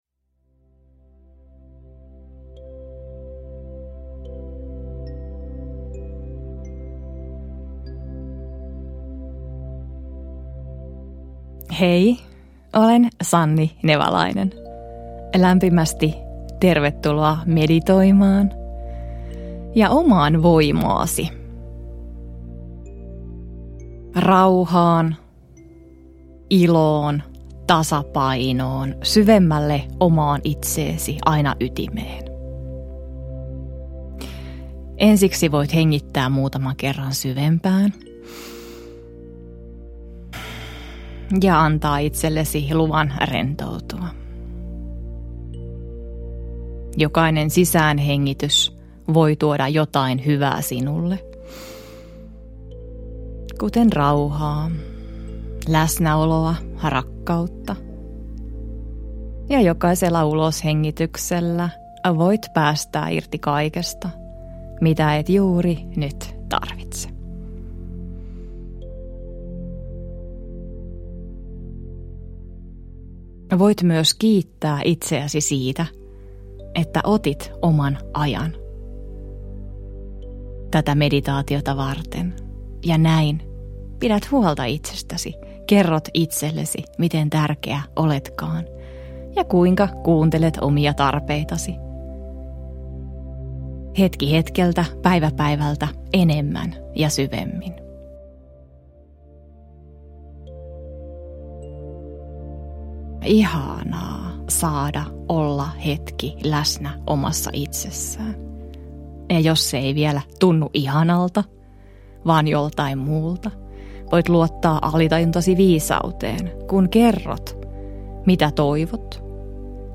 Oma voima ja Elämänpolku -meditaatiot – Ljudbok – Laddas ner
Meditaatioäänite sisältää kaksi noin puolen tunnin syvärentouttavaa meditaatiota.